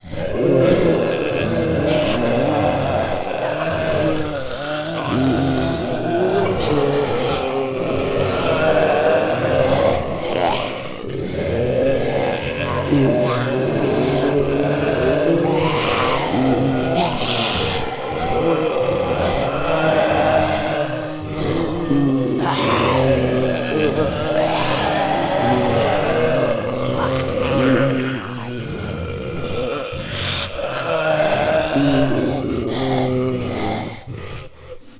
Zombie_02.wav